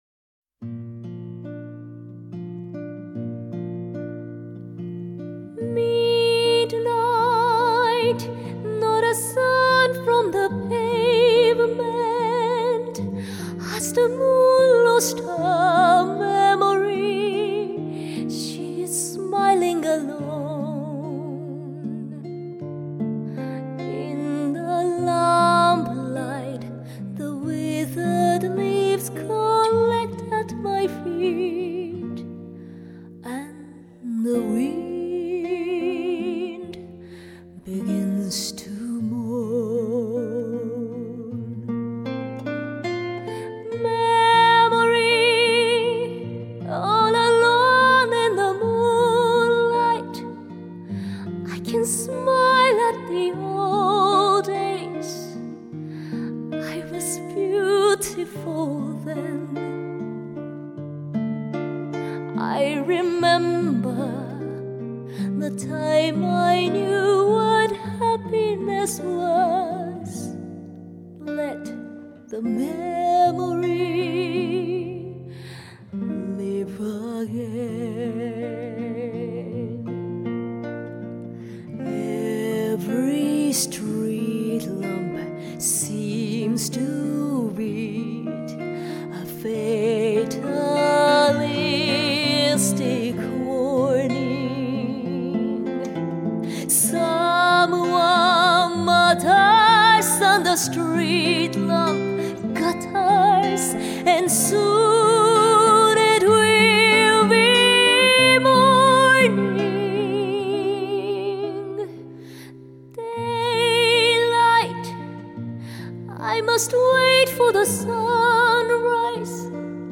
24BIT/192K DSP MASTERING
HQCD格式的试音碟